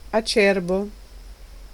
Ääntäminen
US : IPA : [hɑɹd]